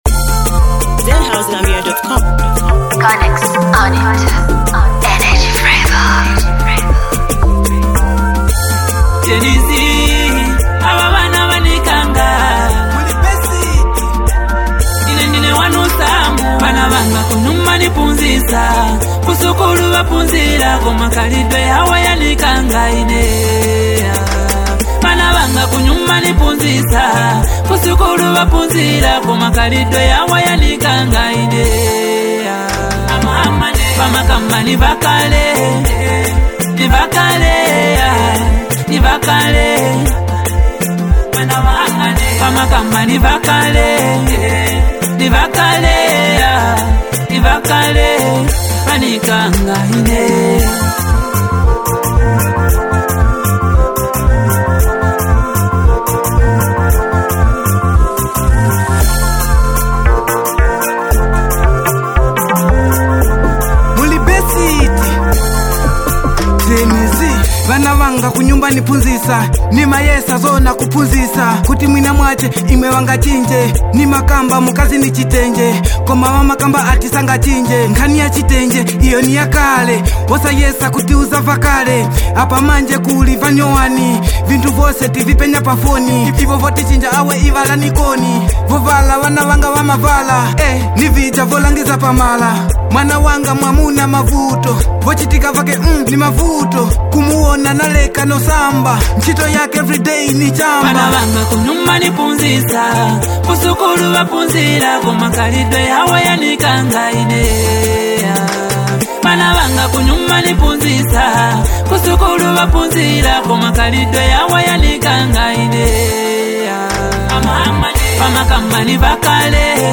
a vibrant track